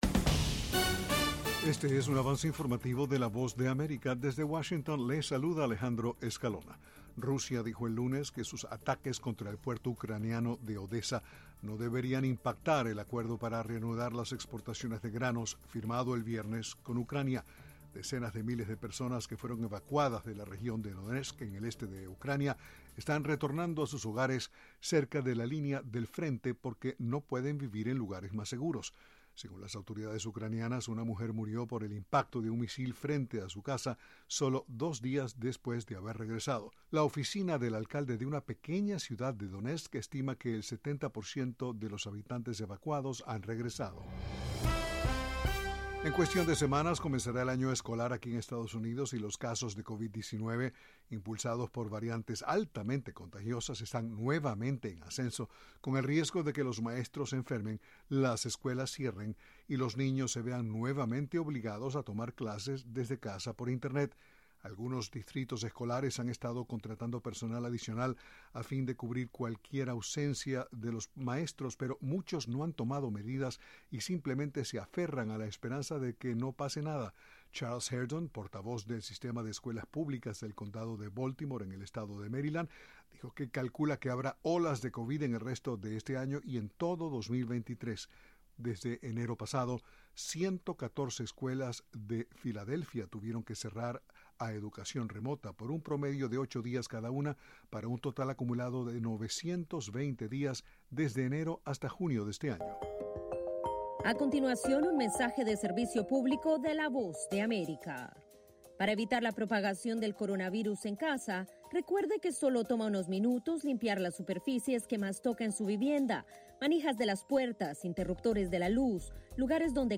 Avance Informativo 4:00pm
Este es un avance informativo presentado por la Voz de América en Washington.